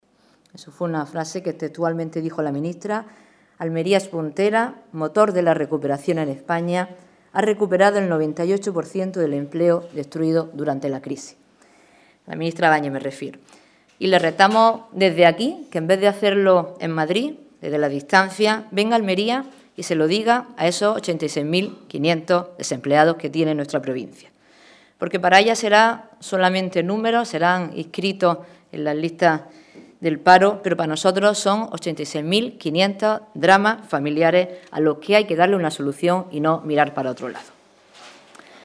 Rueda de prensa de la parlamentaria nacional por el PSOE de Almería, Sonia Ferrer, acompañada del parlamentario Juan Jiménez y el senador Juan Carlos Pérez Navas